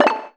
notification-pop-in.wav